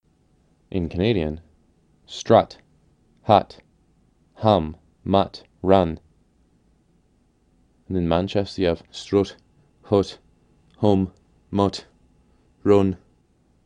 In the city of Manchester, England, words with the vowel heard in Canadian’s “strut” (like hut, hum, mutt, run, etc) sound like how a Canadian would say “foot.”
manchesterShort.m4a